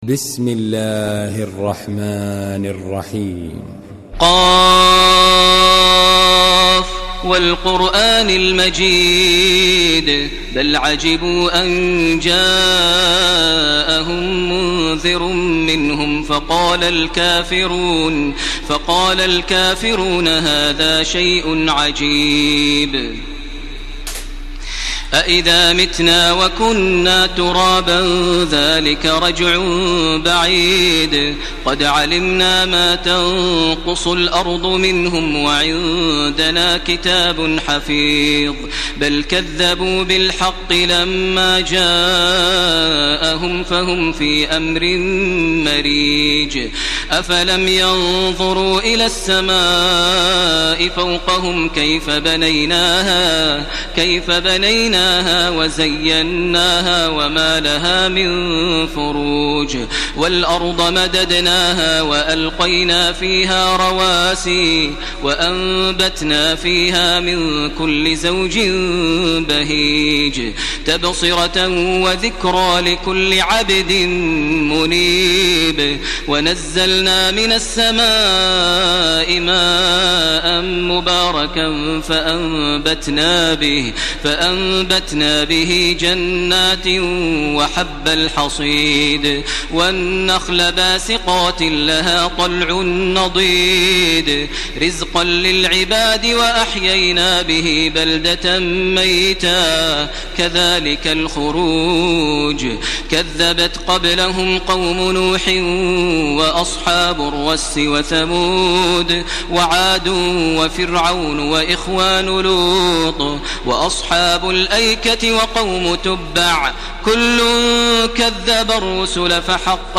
Surah ক্বাফ MP3 by Makkah Taraweeh 1431 in Hafs An Asim narration.
Murattal